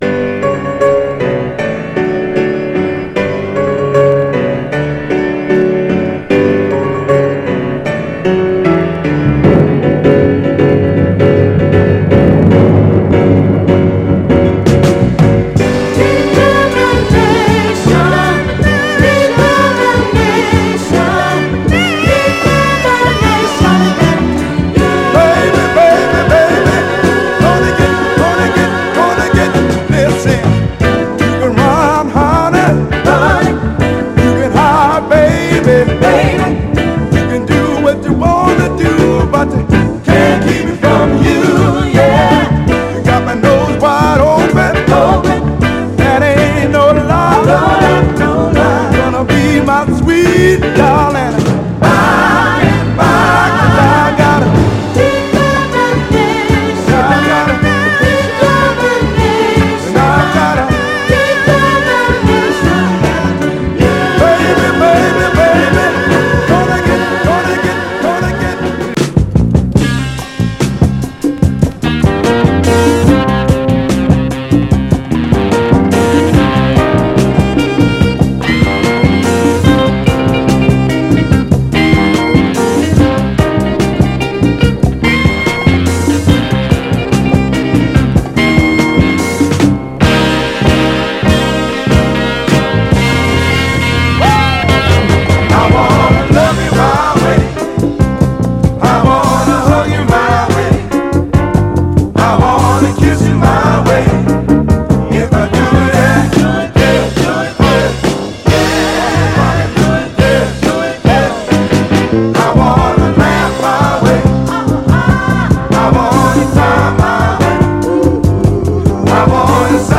ゴスペル・スタイルの壮大なクロスオーヴァー・ソウル"Determinat…